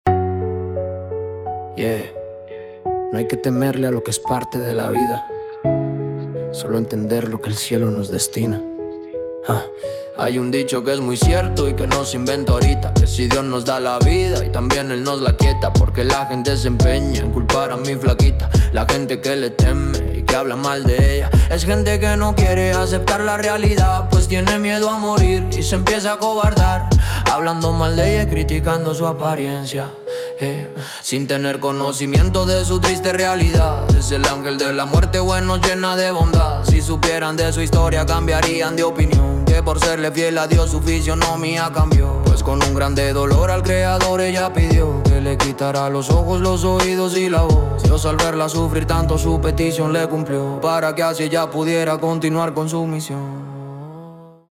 мексиканские , рэп